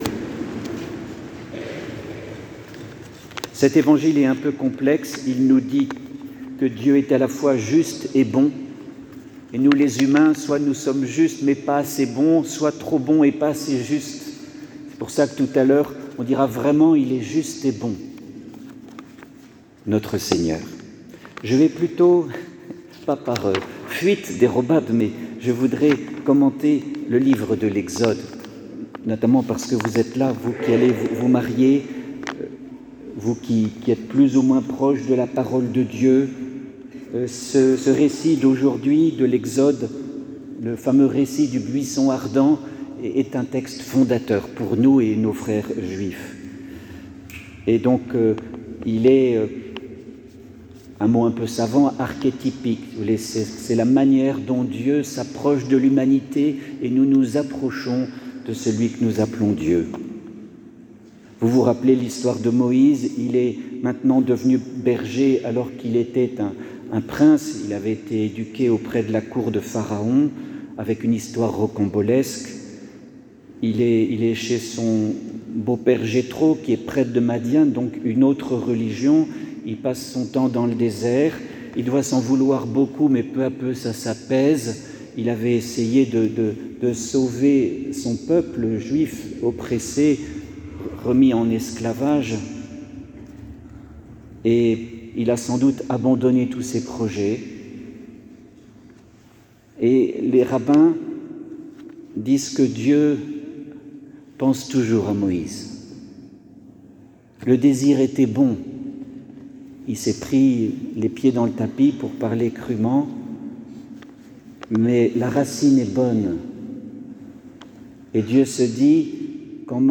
Eglise Saint Ignace
Homelie-buisson-ardent.mp3